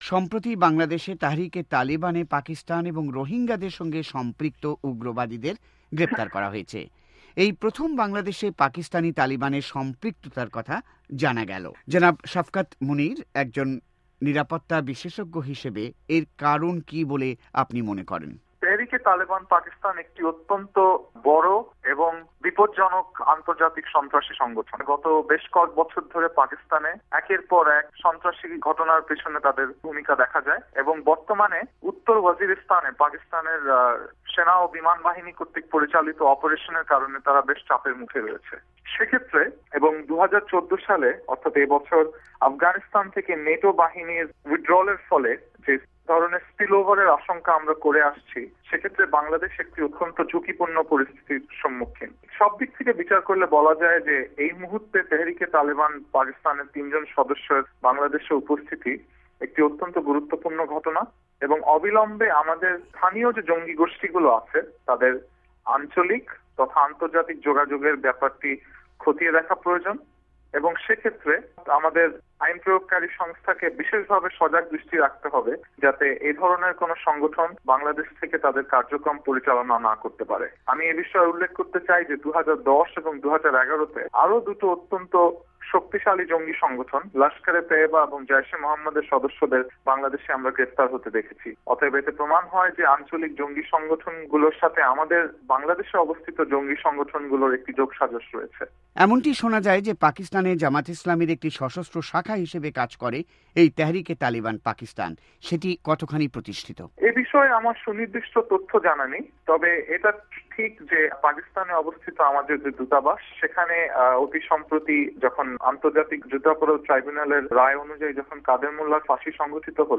একান্ত সাক্ষাৎকারে